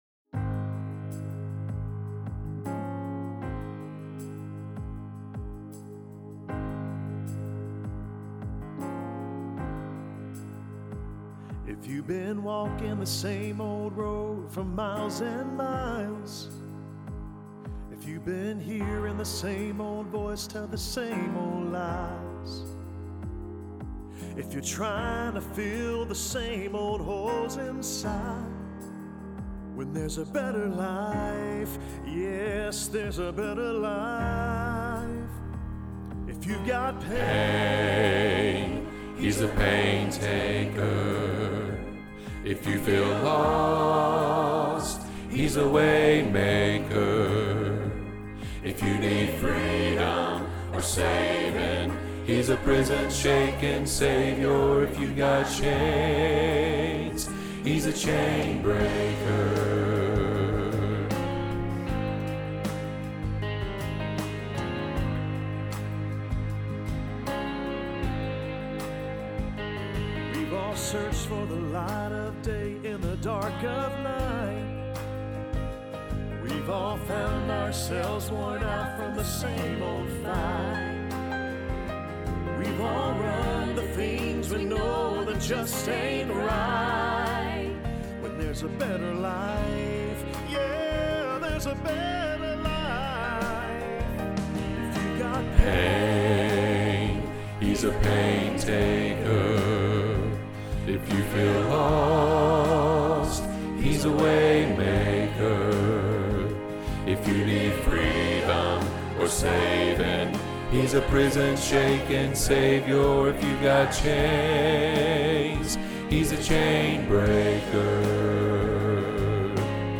Chain Breaker – Bass – Hilltop Choir
Chainbreaker-bass.mp3